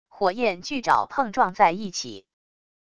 火焰巨爪碰撞在一起wav音频